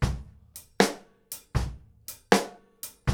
GROOVE 5 04R.wav